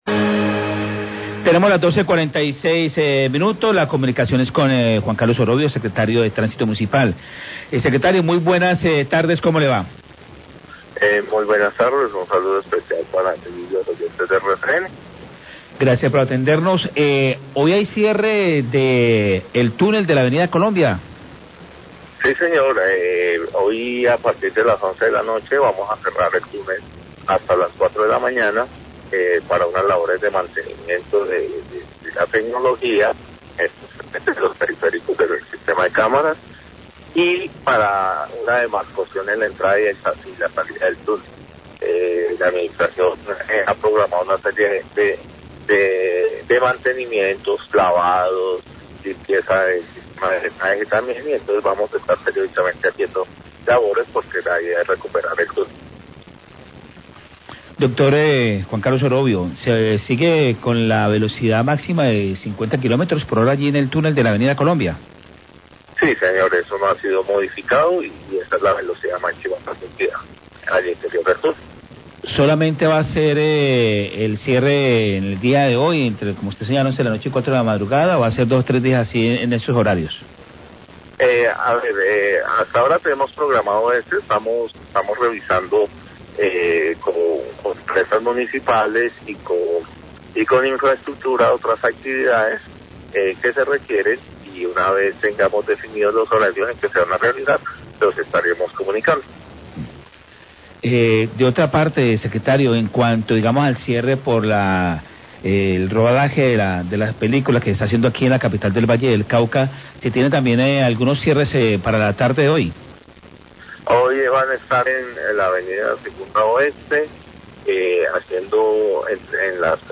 ENTREVISTA A SEC DE TRÁNSITO, 12.46PM